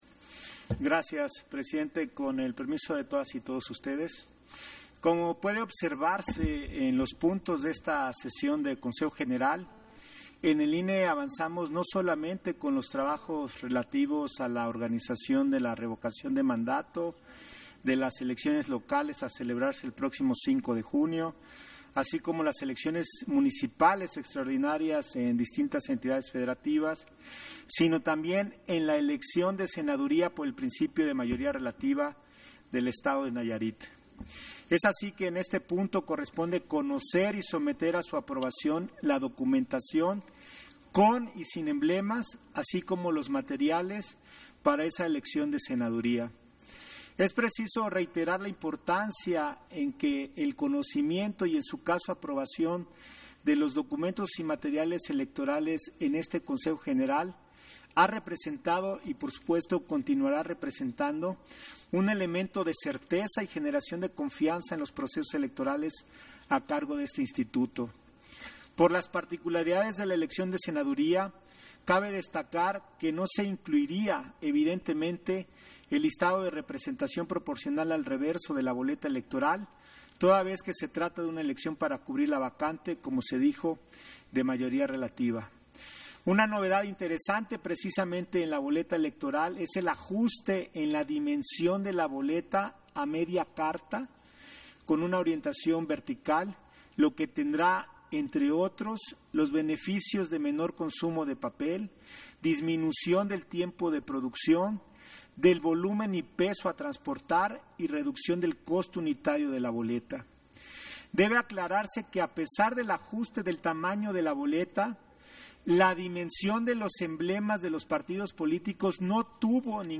Intervención de José Roberto Ruiz, en Sesión Extraordinaria, en el punto en que se aprueba el diseño e impresión de material electoral para la elección extraordinaria para la Senaduría